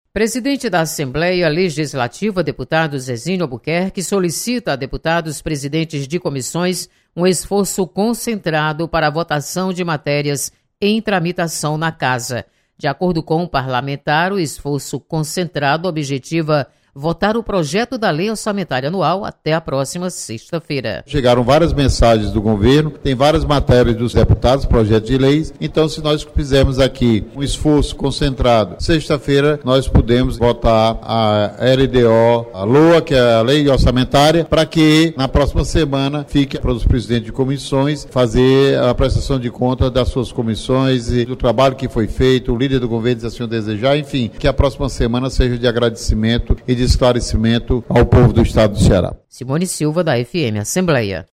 Deputado Zezinho Albuquerque solicita esforço concentrado para encerramento do ano. Repórter